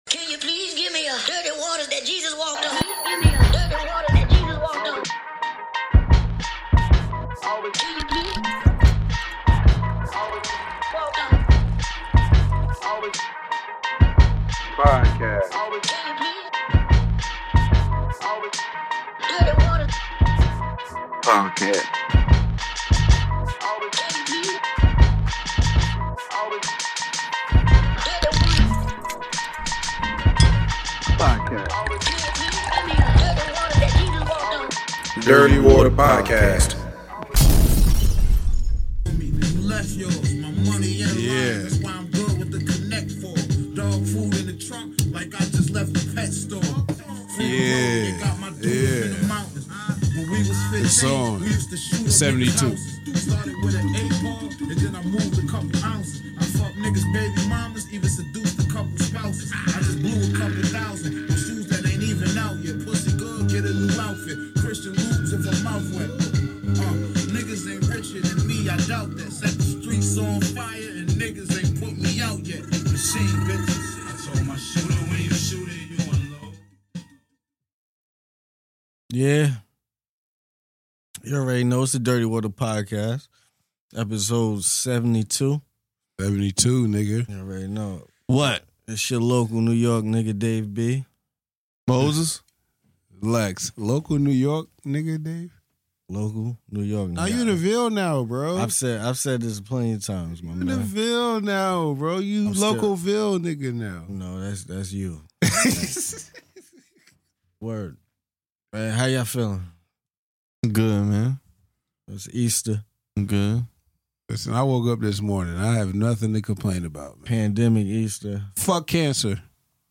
the trio record this episode on Easter Sunday!! The guys speak if they are ready for the summer to come, what will it be like after the COVID-19, the show The First 48, RZA vs Preemo battle on IG live, Tiger King and many more!!!